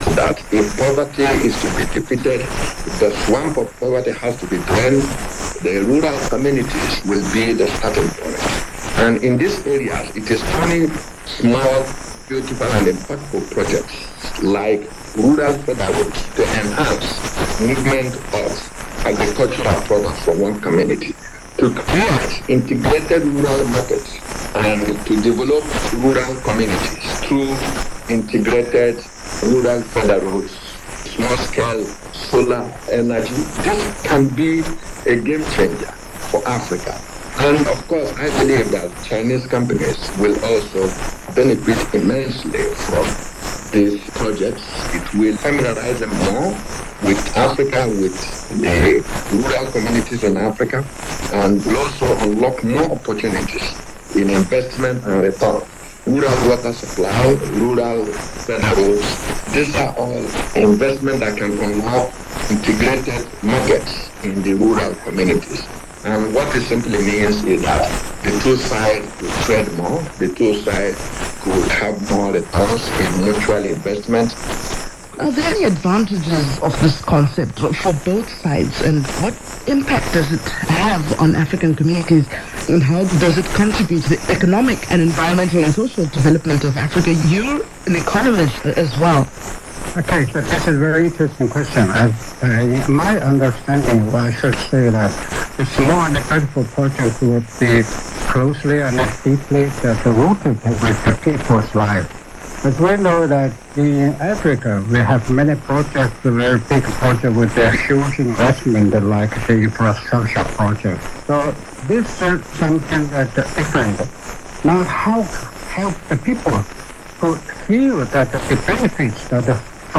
LOC: CHINA, BEIJING, 39.44 N 116.48 E